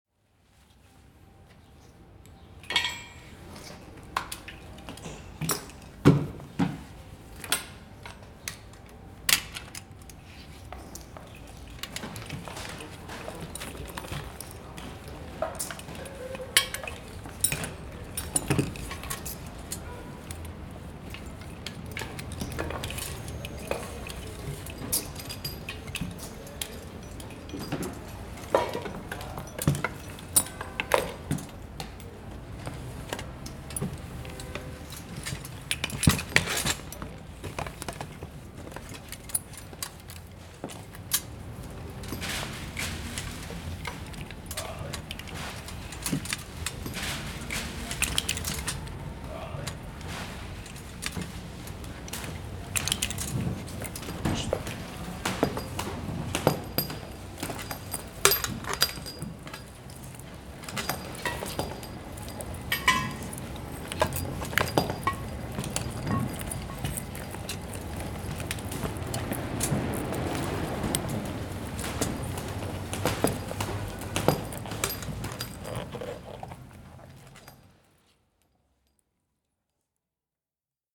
80 x 60 cm picture, text in wall, electroacoustic sound gestures made with field recordings taken from the daily labors of the Guadalajara´s Fireman Department.
Excerpt. A fireman washes his mask.